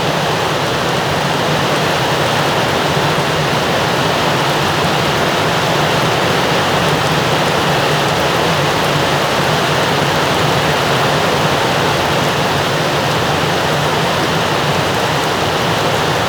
Heavy Rain Ambient Loop 3.wav